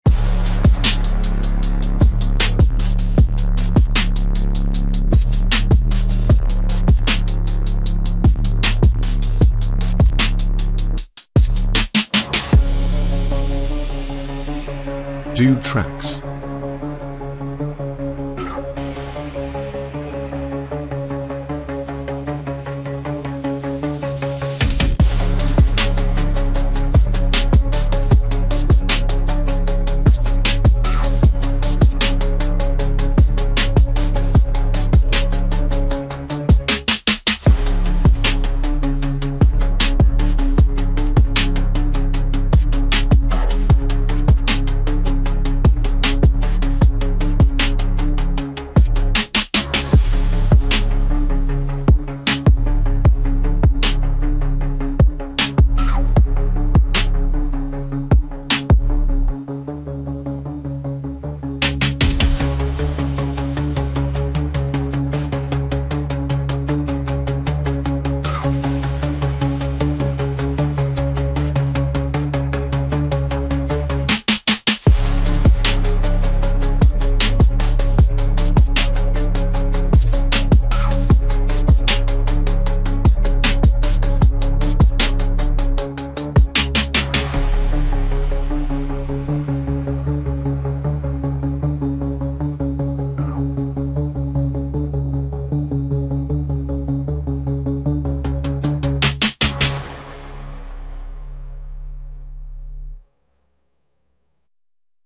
Royalty-Free Hip Hop Beat